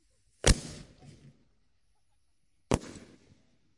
烟花 " 烟花03
描述：使用Tascam DR05板载麦克风和Tascam DR60的组合使用立体声领夹式麦克风和Sennheiser MD421录制烟花。我用Izotope RX 5删除了一些声音，然后用EQ添加了一些低音和高清晰度。
标签： 弹出 焰火 裂纹
声道立体声